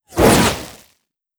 wateryzap2a.wav